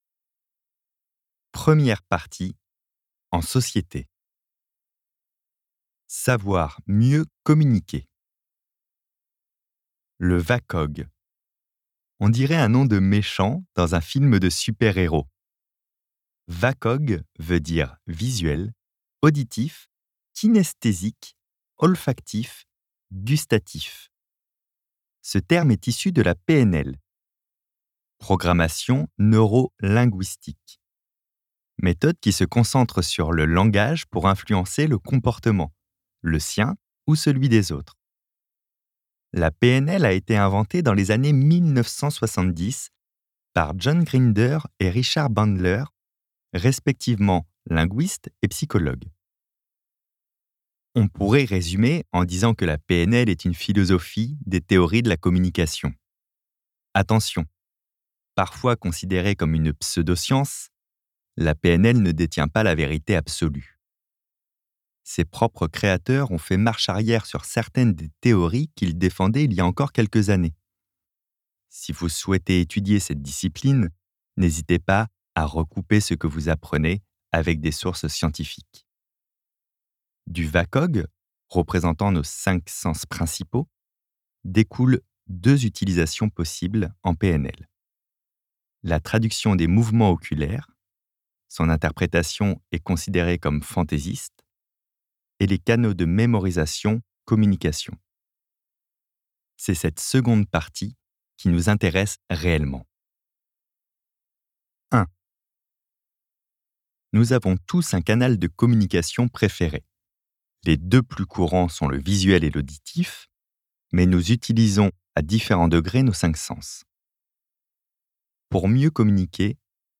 copyparty md/au/audiobook/Fabien Olicard - Votre cerveau est extraordinaire